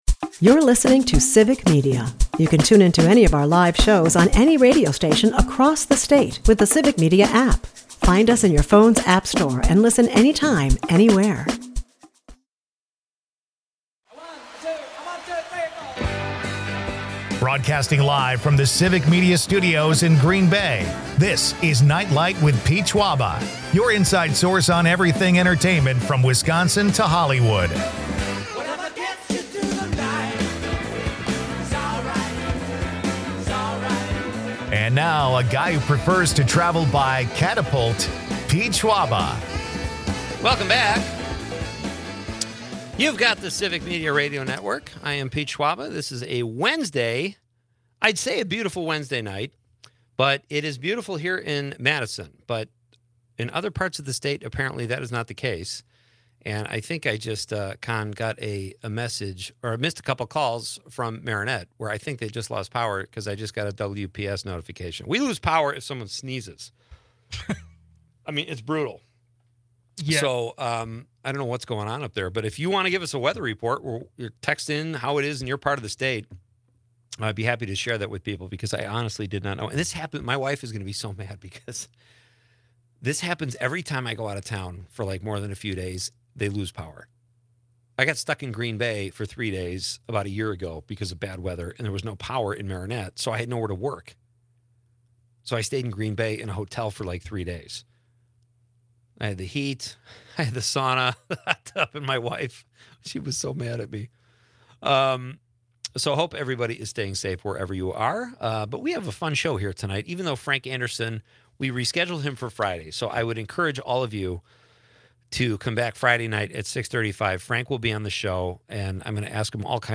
It's a lively mix of music, comedy, and quirky accents.